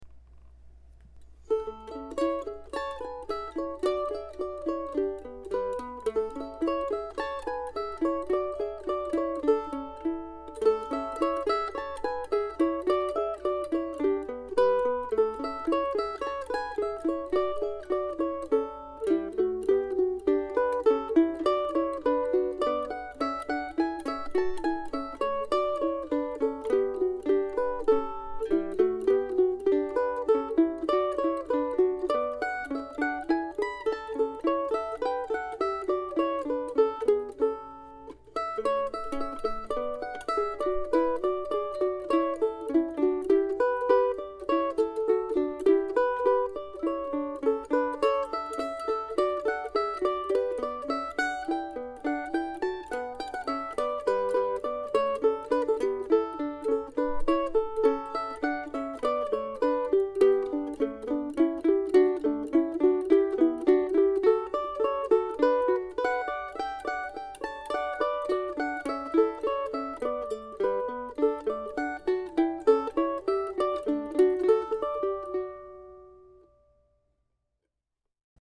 July 27, 2005 Duo July 27, 2005 Duo ( mp3 ) ( pdf ) Another in my summer project to uncover, rework, record and share some short pieces from the past as friendly mandolin duos.